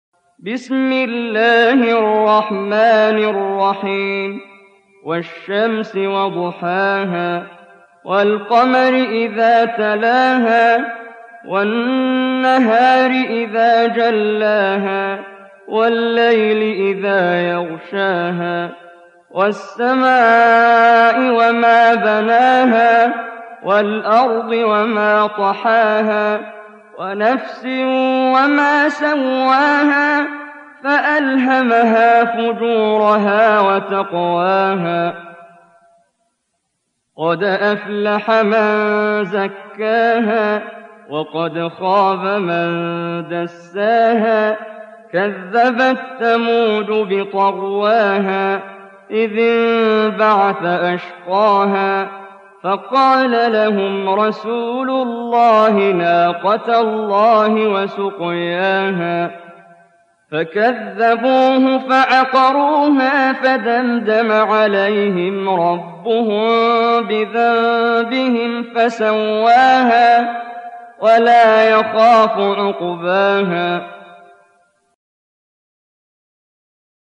91. Surah Ash-Shams سورة الشمس Audio Quran Tarteel Recitation
Surah Sequence تتابع السورة Download Surah حمّل السورة Reciting Murattalah Audio for 91.